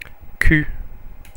Ääntäminen
Ääntäminen France - Paris: IPA: [ku] Haettu sana löytyi näillä lähdekielillä: ranska Käännöksiä ei löytynyt valitulle kohdekielelle.